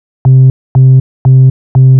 TSNRG2 Off Bass 012.wav